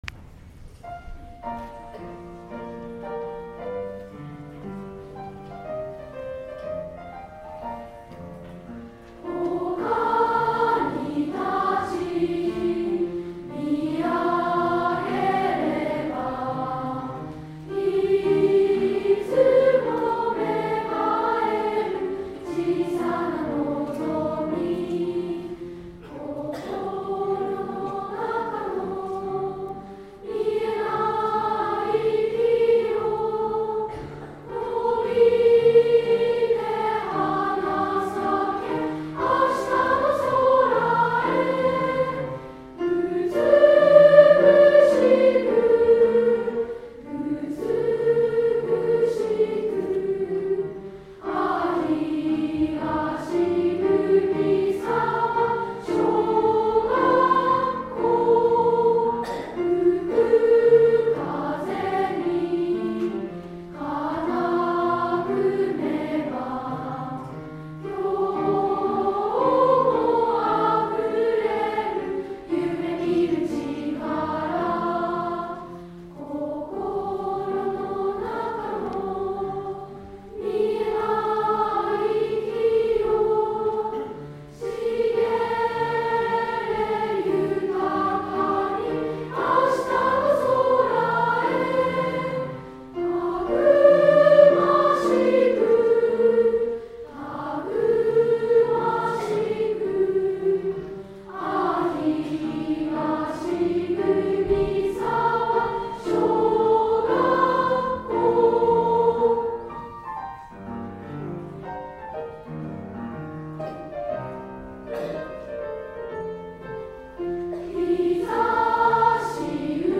みんなの歌声校歌
作詞：伊藤　海彦
作曲：平井哲三郎